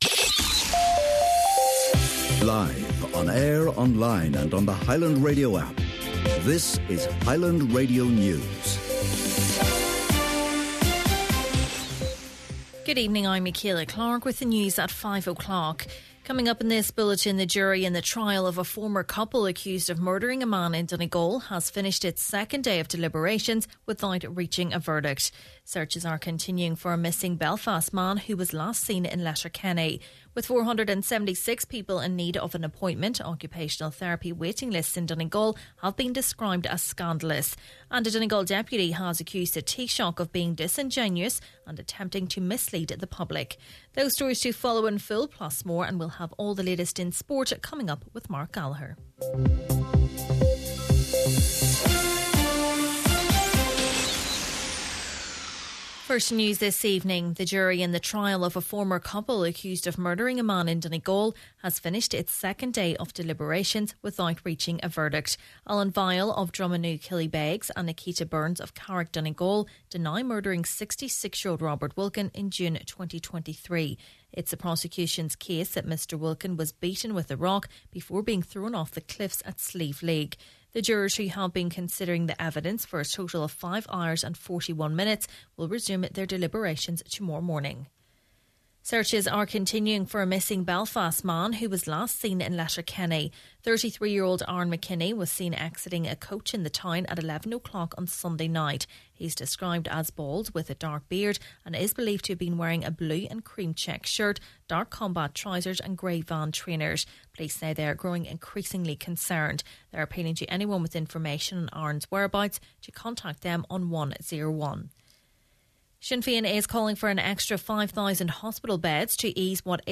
Main Evening News, Sport and Obituaries – Wednesday, 5th March